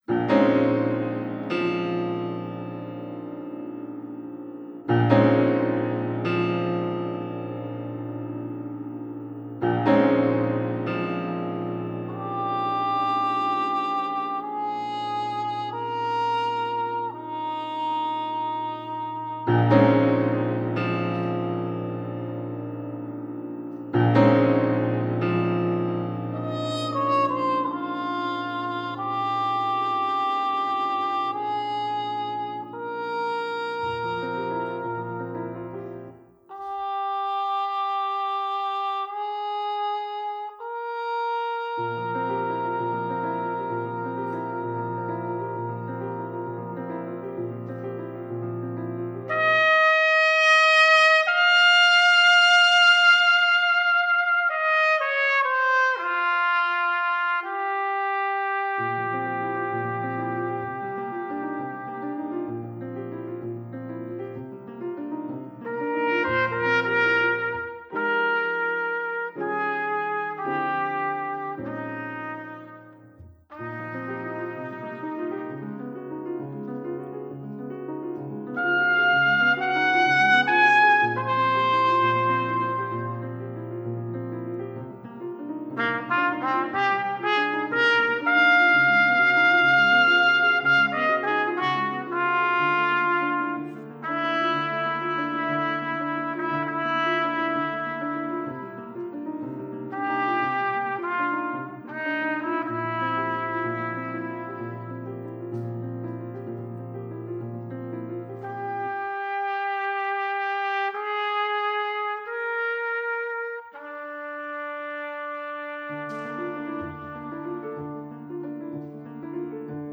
In fact when I tried the improvised section of Kenny Wheeler’s piece, I stumbled on to the opening ideas to my piece for trumpet and piano call “Fractured Trance”.33010
The opening idea lead me to explore more ideas around rhythm and space within that movement.